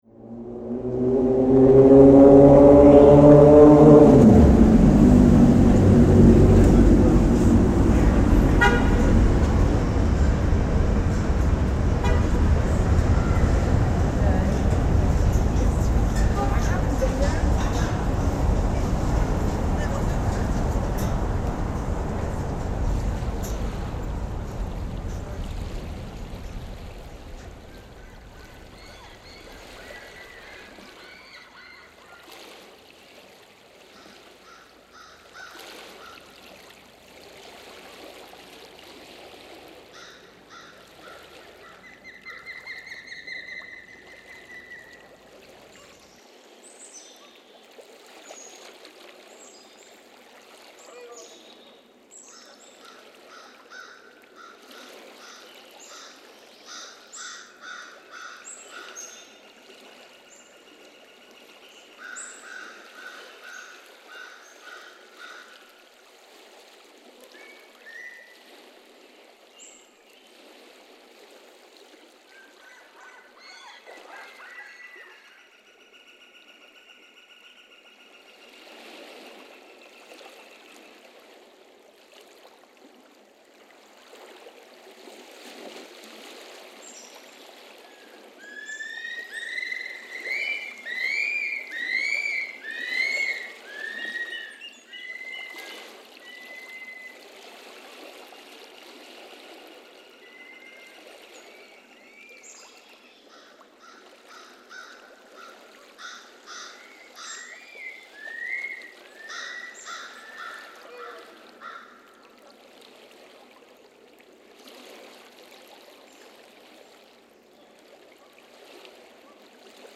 Unsung NYC is a cool new virtual reality project which combines 360° video along with immersive, binaural audio to help recreate the historic soundscapes of New york City areas.
Explore New York's High Line Park as it was in 1609 through this 360 soundscape. From Calling Thunder: The Unsung History of New York
High_Line_Soundscape_Un